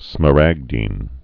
(smə-răgdēn)